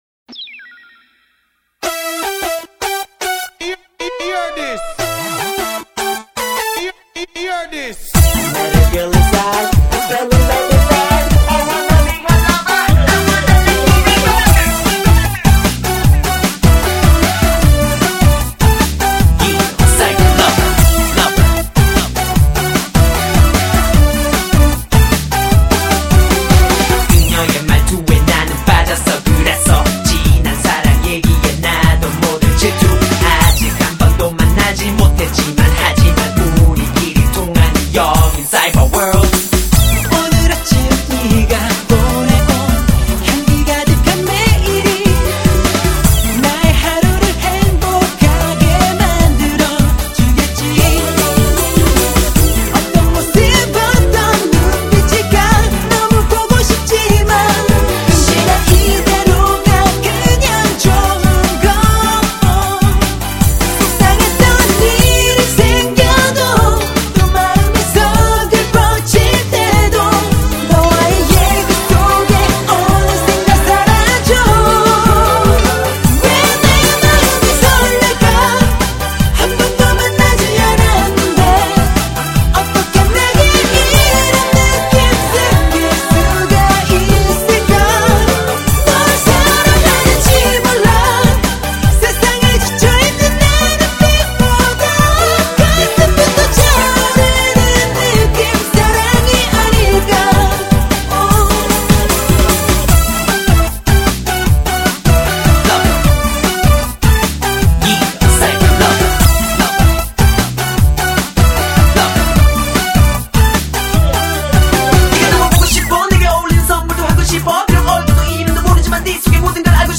BPM：152
主唱及编舞
Rap及编舞
歌曲的旋律性都很强